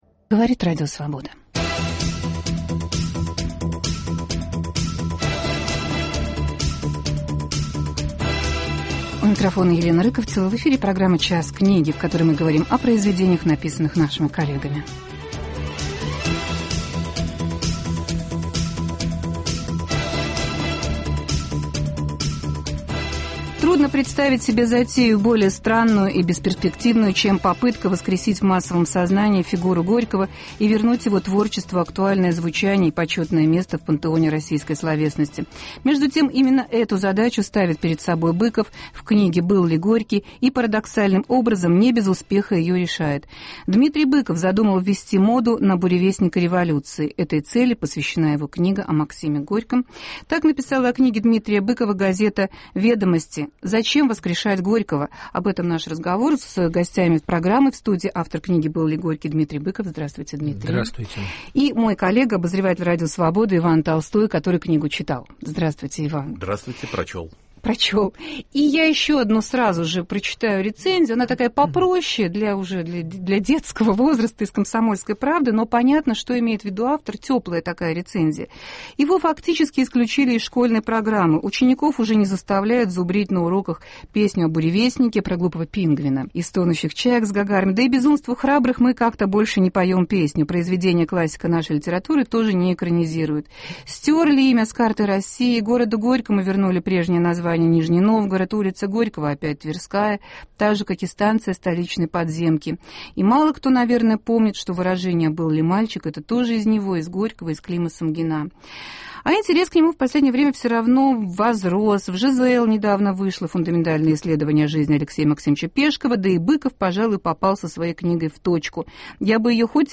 Обсуждаем книгу Дмитрия Быкова "А был ли Горький?" В студии автор - Дмитрий Быков, и рецензент - писатель Иван Толстой.